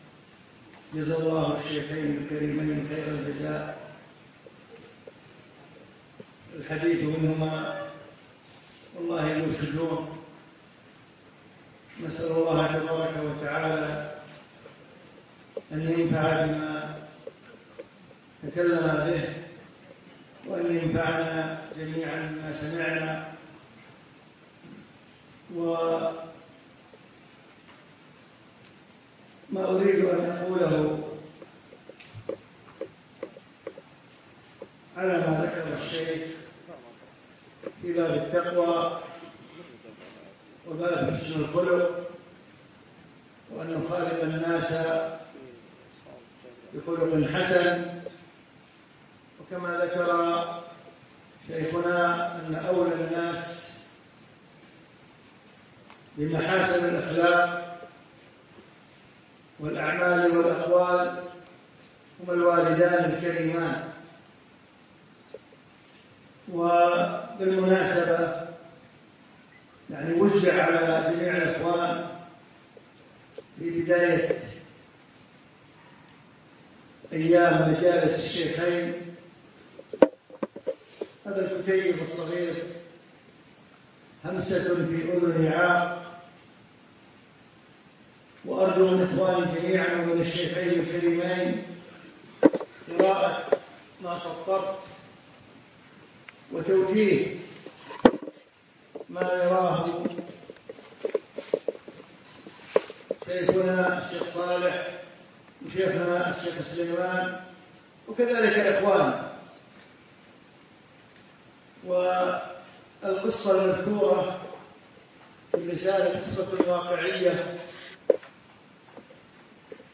احرص على برِّ والديك - كلمة